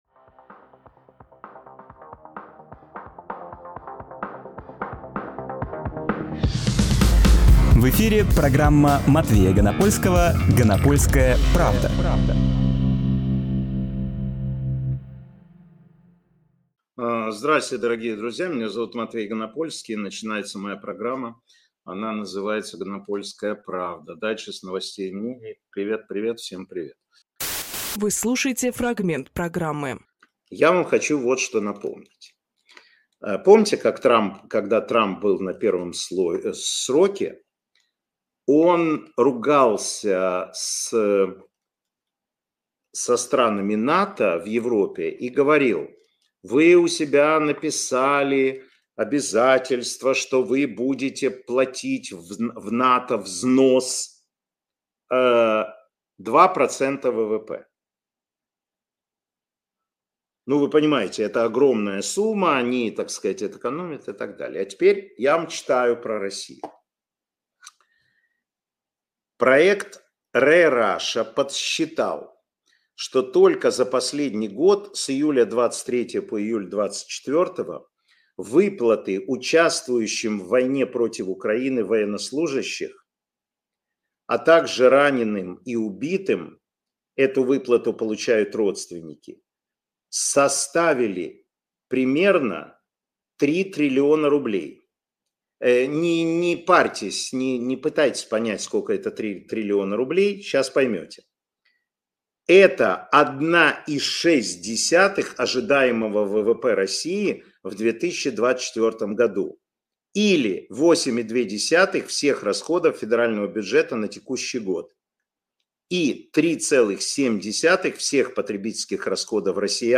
Фрагмент эфира от 17.07.24